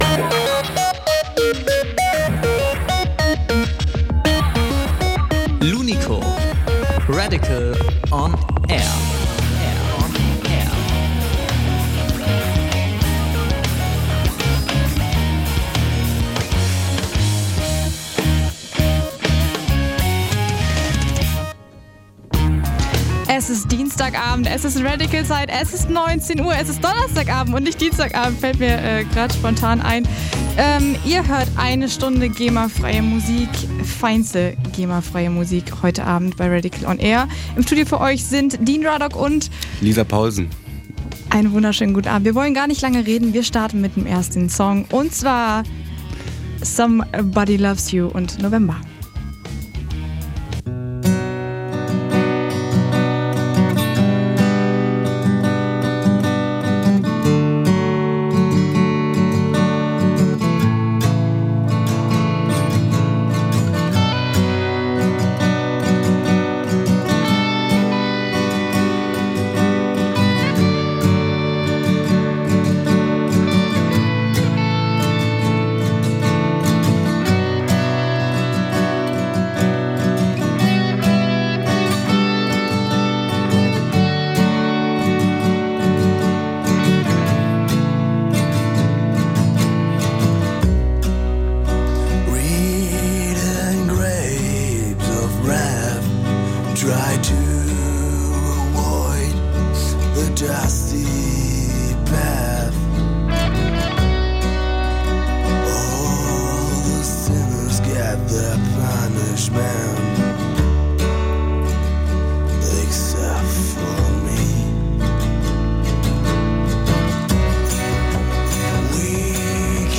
live im Interview